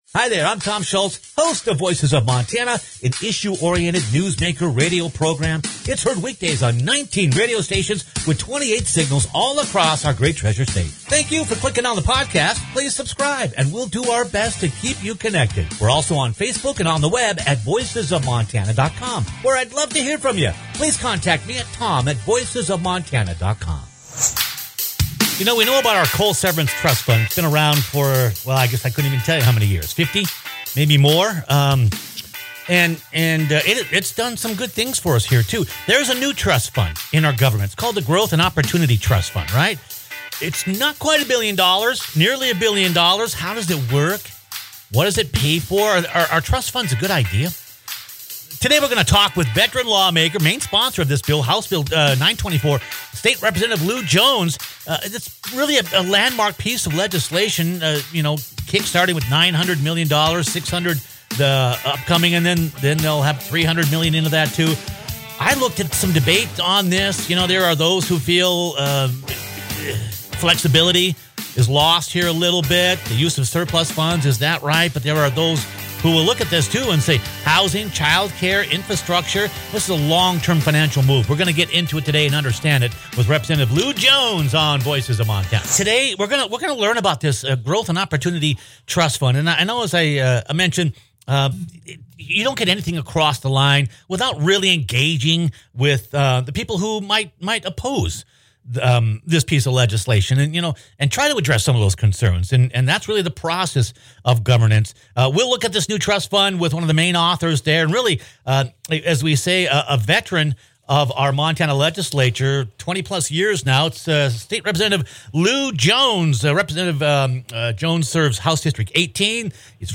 Click on the podcast to hear veteran lawmaker and main sponsor, State Representative Llew Jones talk about this landmark piece of legislation that will support housing, childcare, infrastructure, pensions,